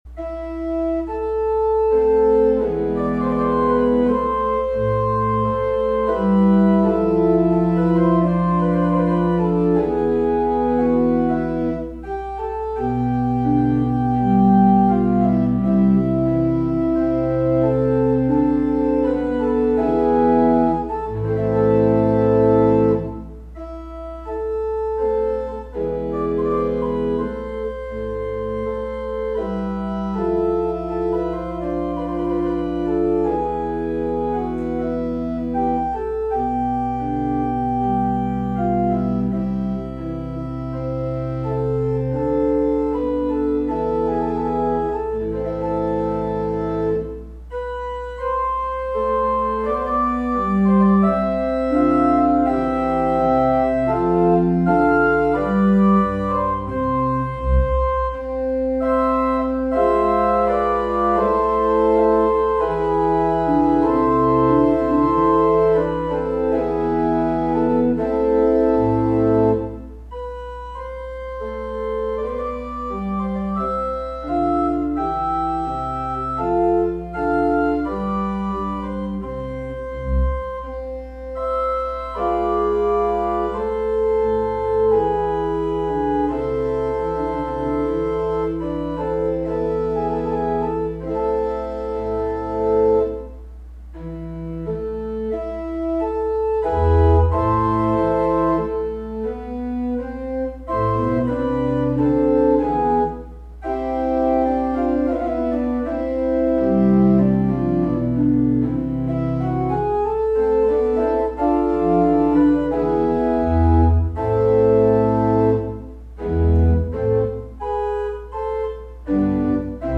Music for Meditation – AWR - Instrumental Music3 – Lyssna här